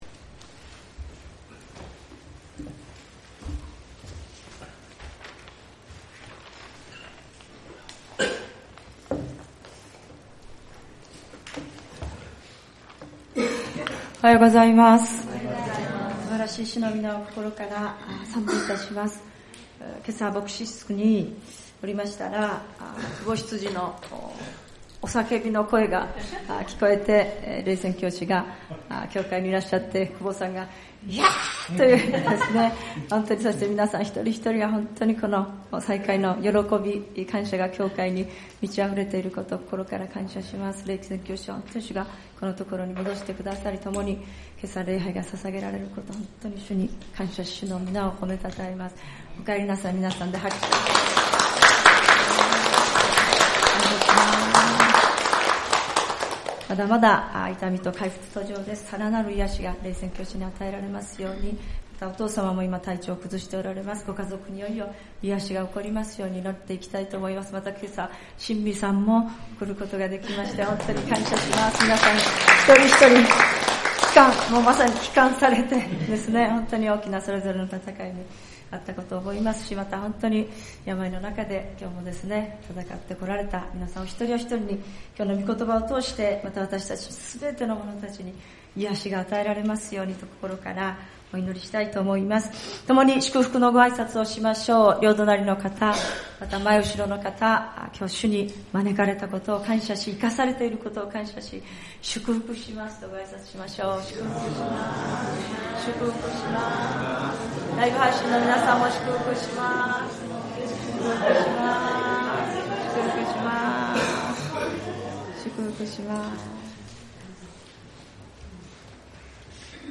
聖日礼拝「真の癒しを求めて